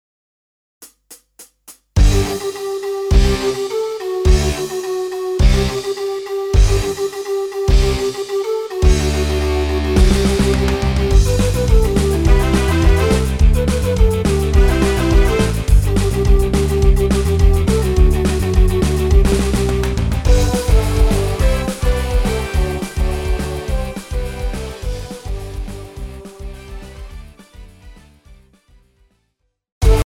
Žánr: Punk
BPM: 210
Key: G
MP3 ukázka s ML